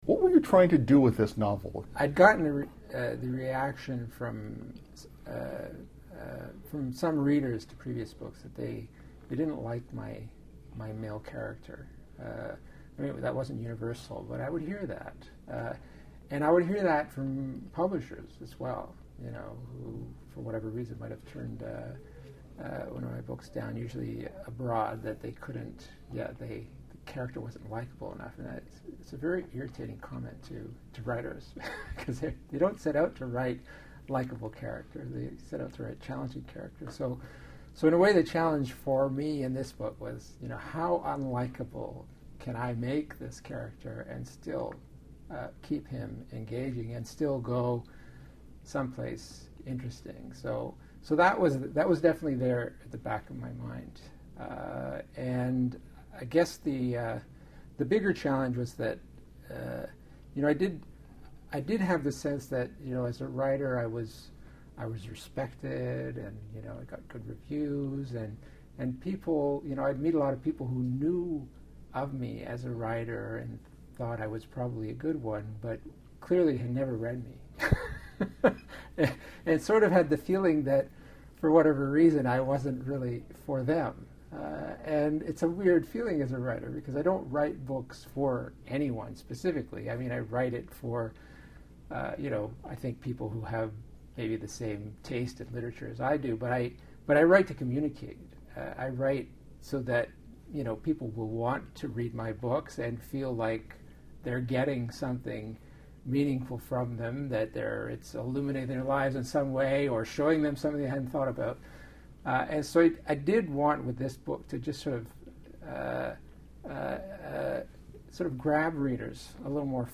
Recording Location: Toronto
Featured Speakers/Guests: Award-winning novelist Nino Ricci
Type: Interview
128kbps Stereo